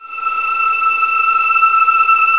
Index of /90_sSampleCDs/Roland LCDP13 String Sections/STR_Violins I/STR_Vls2 Arco